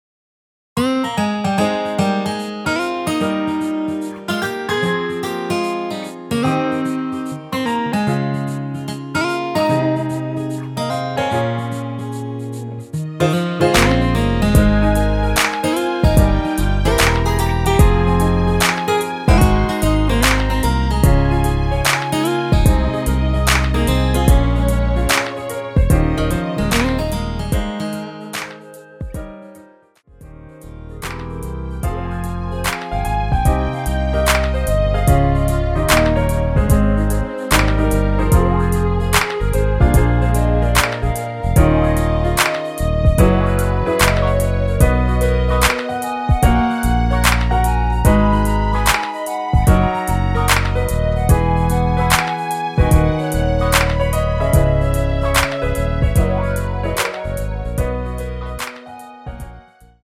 원키에서(+5)올린 멜로디 포함된 MR입니다.
Ab
앞부분30초, 뒷부분30초씩 편집해서 올려 드리고 있습니다.